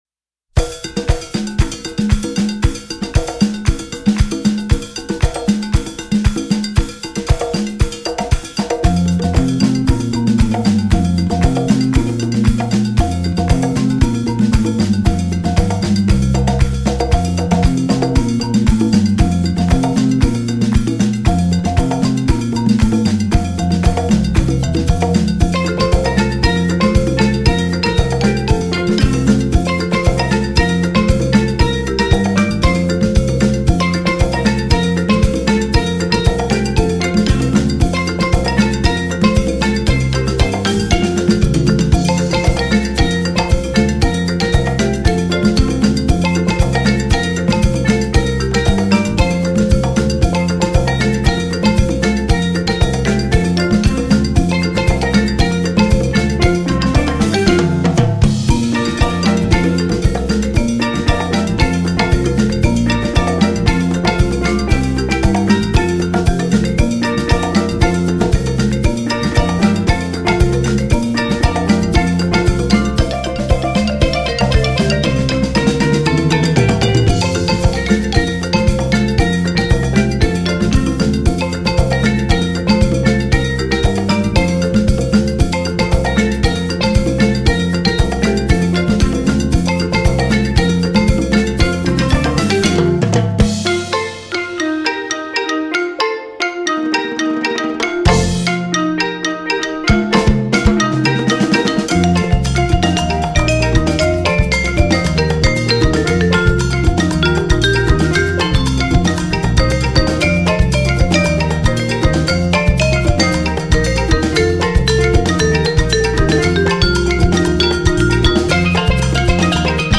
Voicing: Percussion Decet